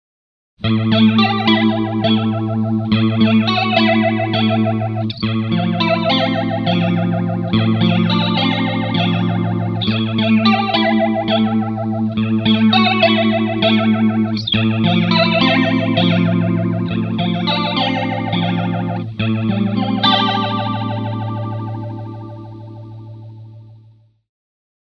Wah Pedal
- Vintage Inductor-Transistor Wah
Demo with Single Pickup